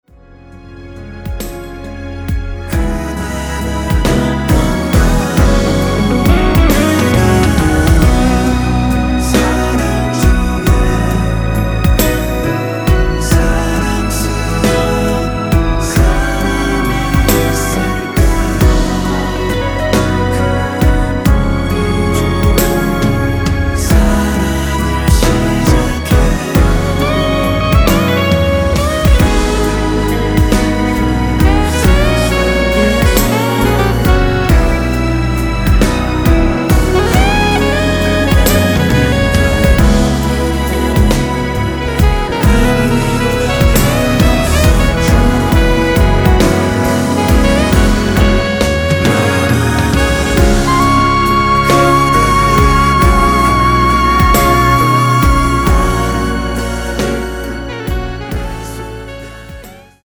순수 코러스만 들어가 있으며 멤버들끼리 주고 받는 부분은 코러스가 아니라서 없습니다.(미리듣기 확인)
원키 코러스 포함된 MR입니다.
앞부분30초, 뒷부분30초씩 편집해서 올려 드리고 있습니다.
중간에 음이 끈어지고 다시 나오는 이유는